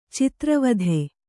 ♪ citra vadhe